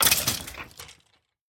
Sound / Minecraft / mob / skeleton / death.ogg
death.ogg